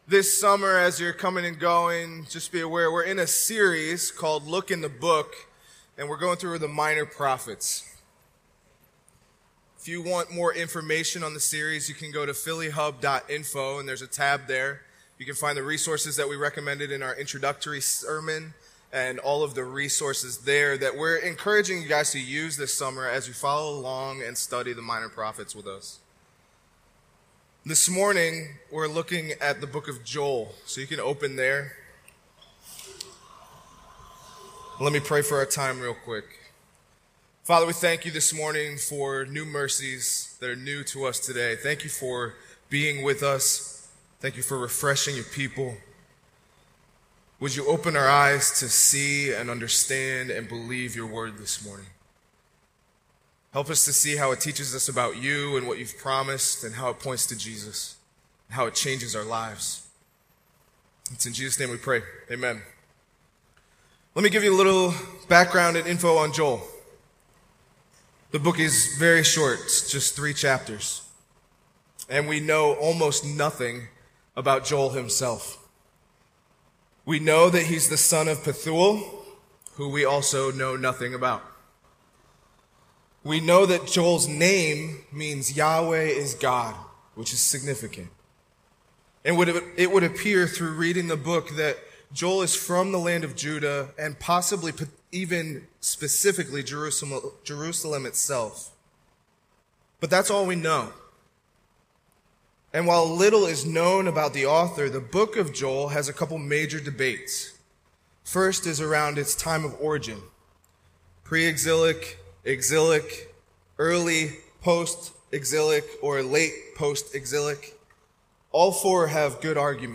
The Minor Prophets " "Look in the Book" is an ongoing summer series of sermons focusing on overviews in particular sections of the Bible.